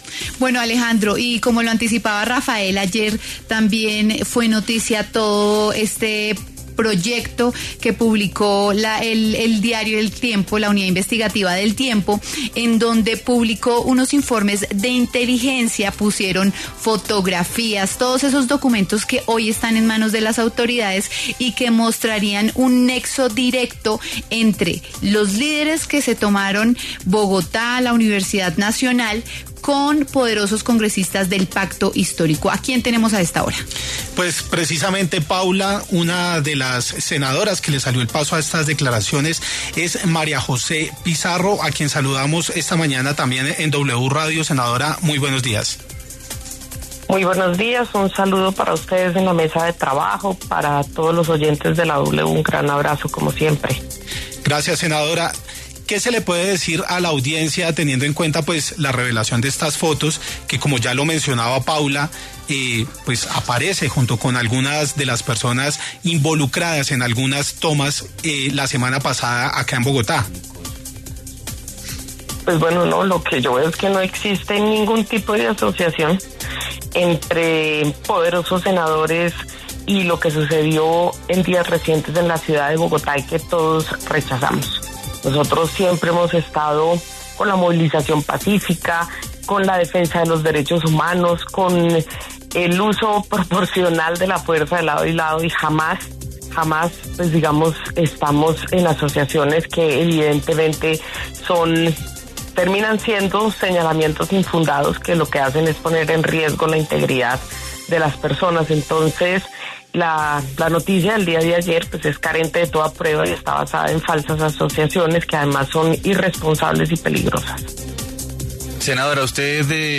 La senadora María José Pizarro conversó con W Fin de Semana acerca de una serie de fotografías en las que aparecería junto a algunos de los voceros de las tomas y protestas que se registraron en Bogotá.